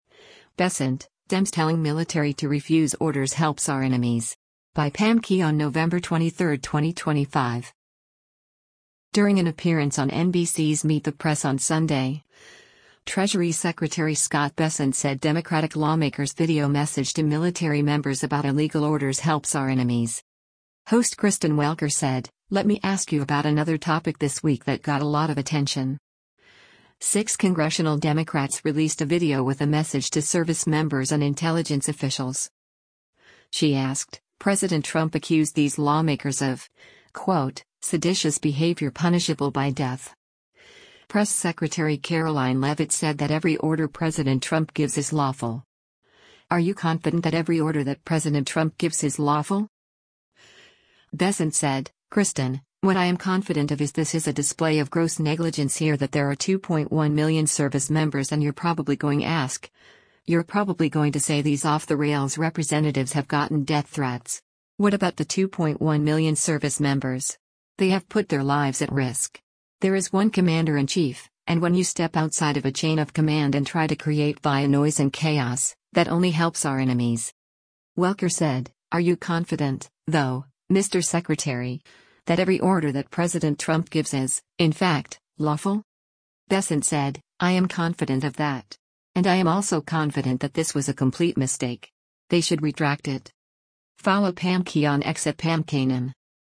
During an appearance on NBC’s “Meet the Press” on Sunday, Treasury Secretary Scott Bessent said Democratic lawmakers’ video message to military members about illegal orders “helps our enemies.”